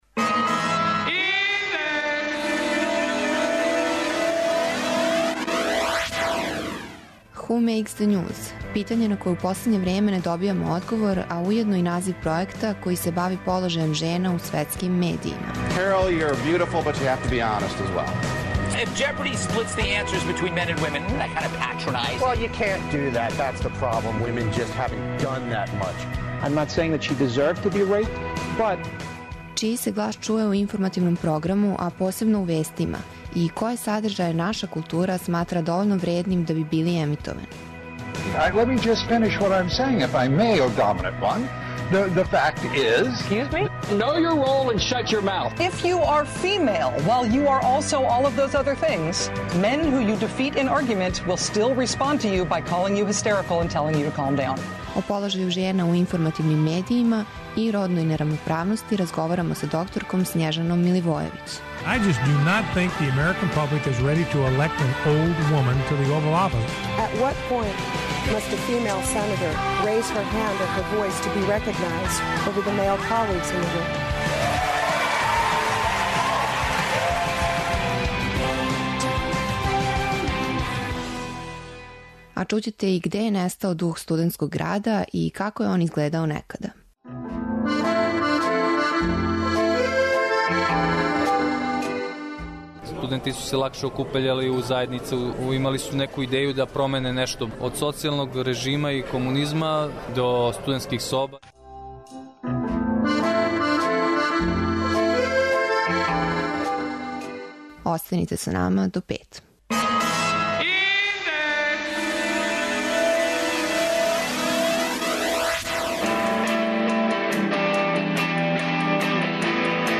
''Индекс 202'' је динамична студентска емисија коју реализују најмлађи новинари Двестадвојке.